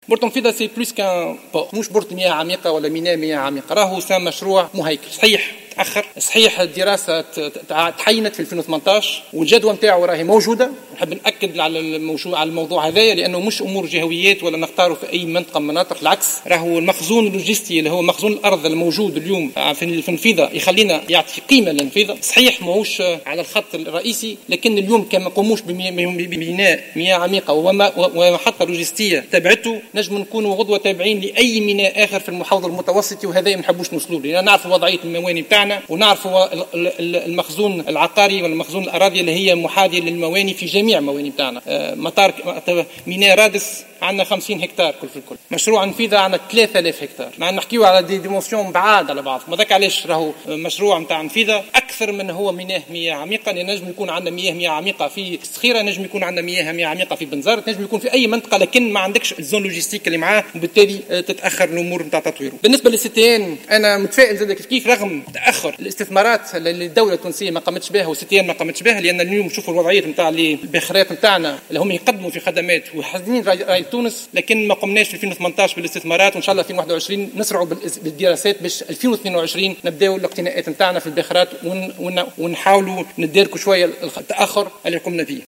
وشدد شقشوق، في إجابته على أسئلة عدد من نواب الشعب خلال جلسة عامة عقدها البرلمان لمناقشة مهمة وزارة النقل واللوجستيك، على ان اهمية ميناء المياه العميقة بالنفضية تعود الى توفر رصيد عقاري يمتد على 3 ألاف هكتار مما يوفر منطقة لوجستية لا تتوفر في عدة مواقع اخرى.